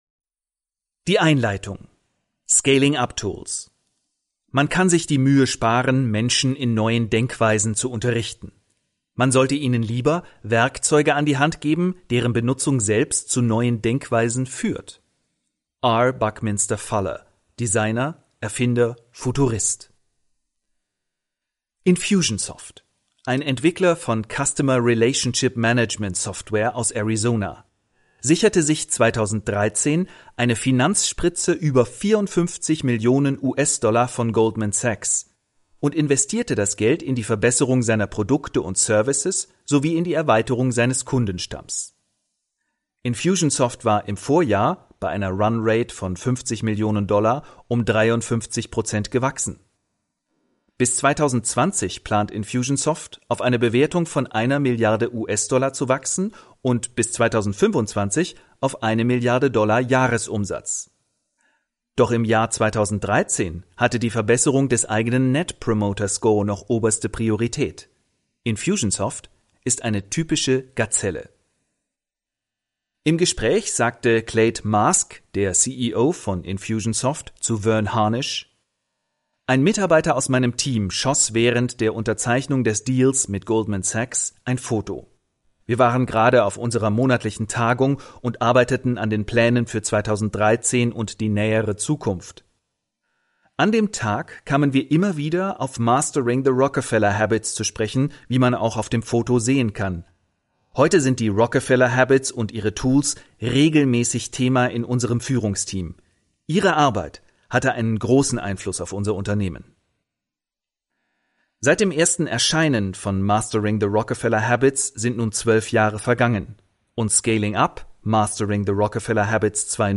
Unternehmer kommen an diesem Hörbuch nicht vorbei